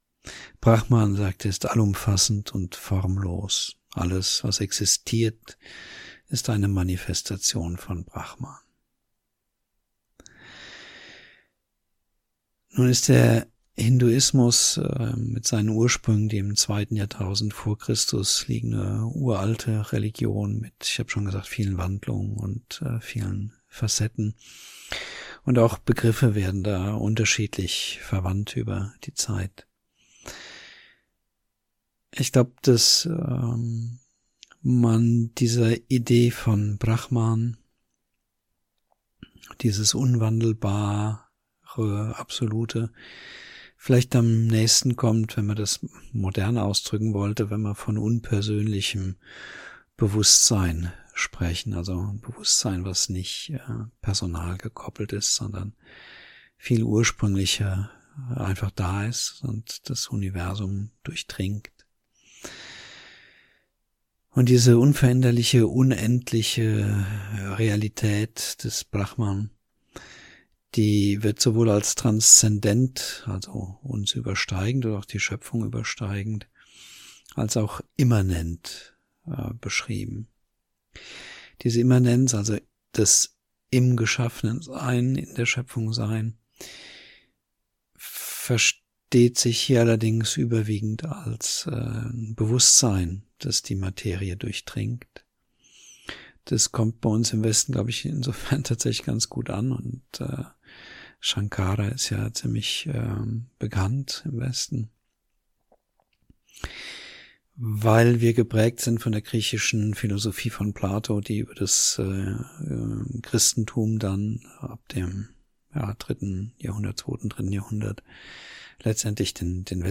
01 Brahman als höchste Realität (Vortrag)   16min